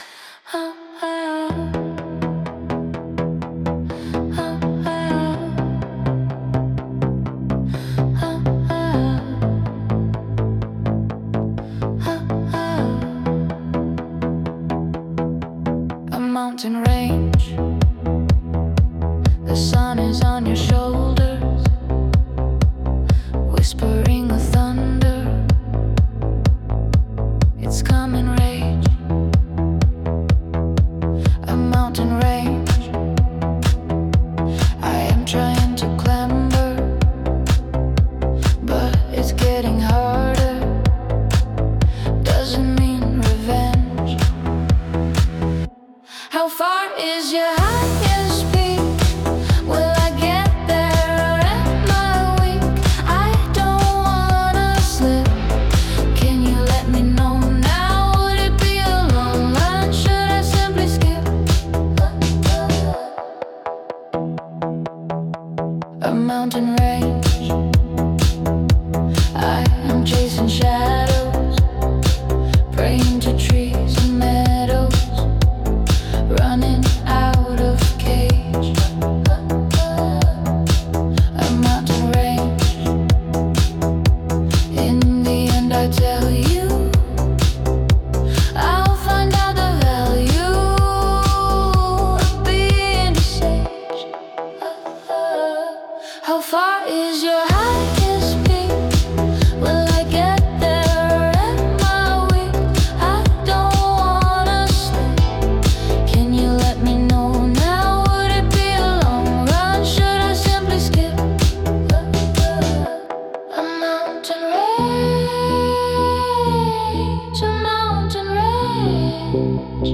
техно-поп, быстрый темп, жёсткий кик, моторный грув, сухие ударные, минимальная перкуссия в куплете, плотнее в припеве